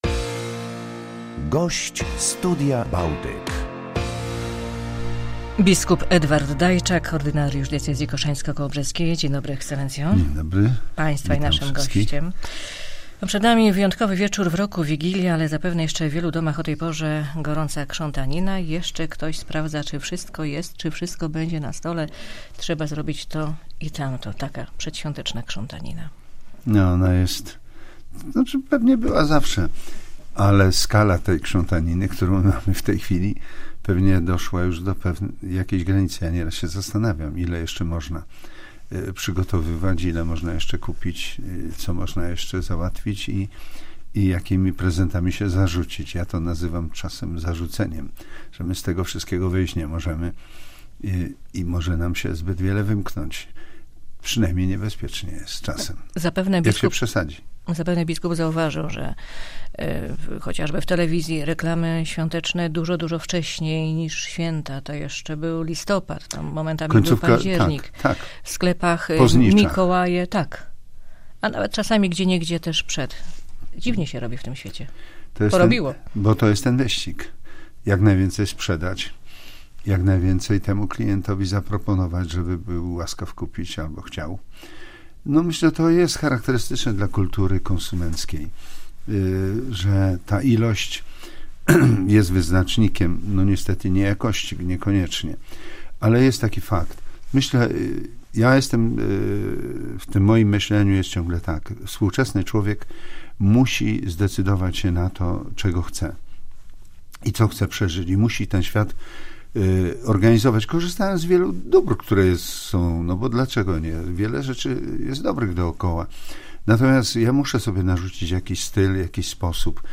Ordynariusz diecezji koszalińsko-kołobrzeskiej 24 grudnia był gościem porannego "Studia Bałtyk" w Polskim Radiu Koszalin.
/files/media/pliki/BpEDWigilia2018.mp3 Materiał udostępniony dzięki uprzejmości Polskiego Radia Koszalin.